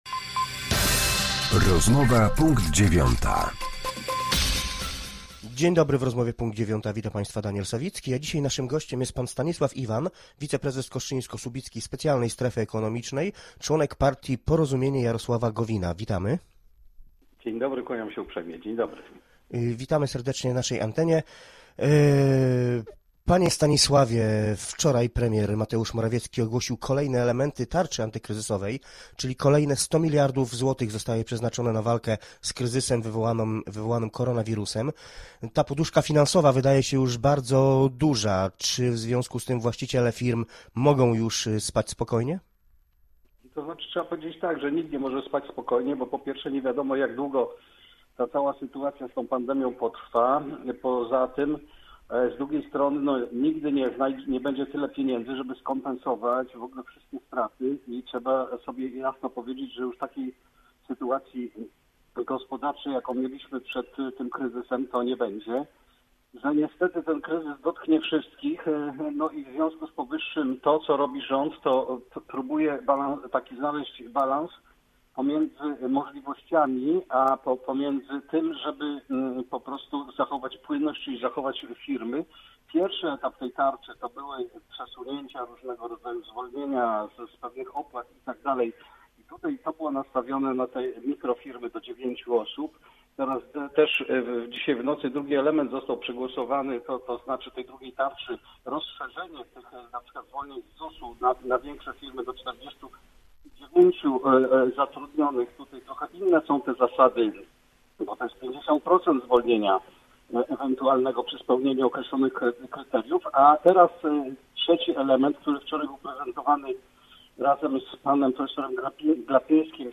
Z wiceprezesem Kostrzyńsko-Słubickiej Specjalnej Strefy Ekonomicznej, członkiem partii Porozumienie